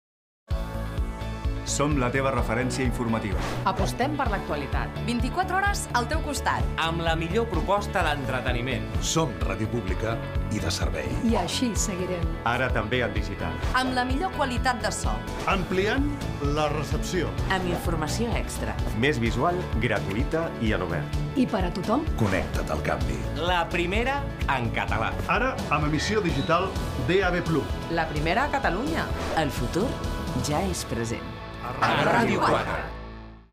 Anunci televisiu de TVE a Catalunya de l'inici de les emissions en DAB+